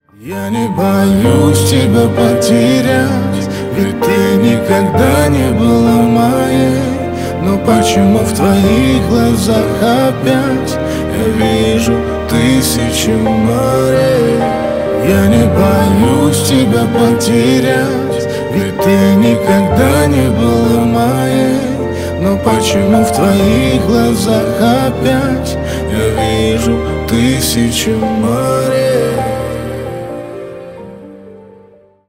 Поп Музыка
грустные # спокойные # кавер